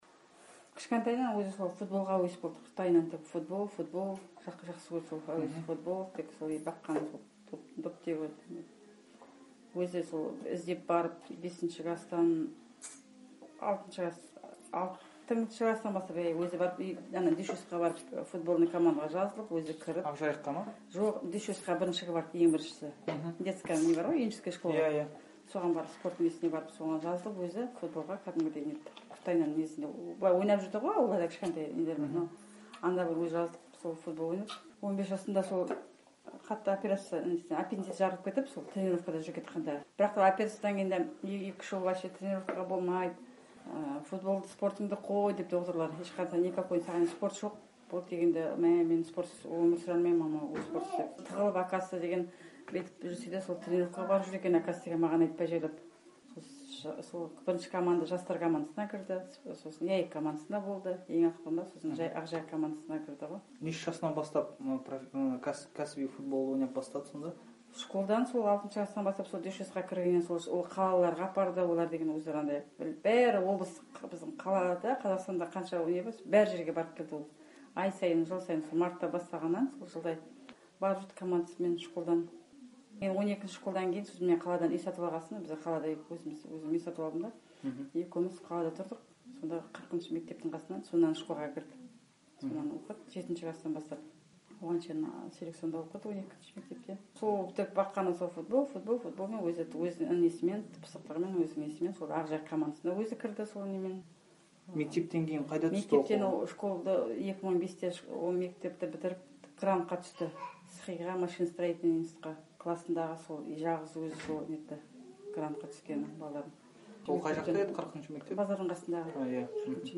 Оралда туып-өскен, Сирияға «жиһадқа» кетті делінген бұрынғы футболшының анасы Азаттыққа бөгде елдегі соғыста жалғыз ұлынан айрылудың қаншалықты қиын екенін айтып берді.